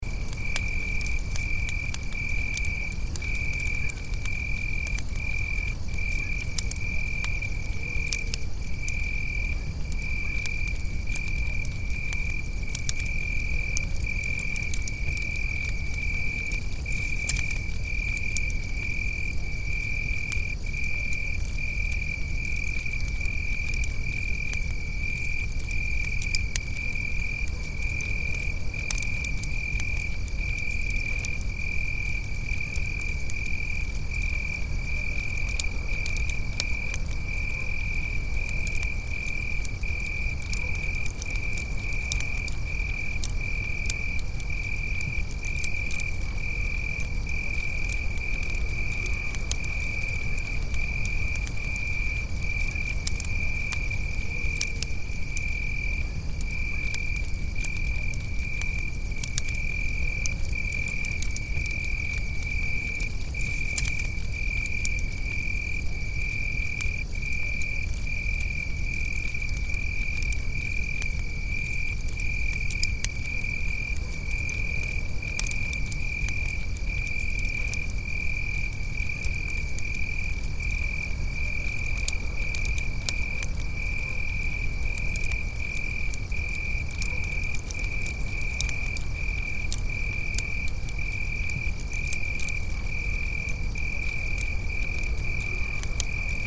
Download Free Fire Sound Effects | Gfx Sounds
Relaxing-campfire-night-cozy-ambience-loop-3.mp3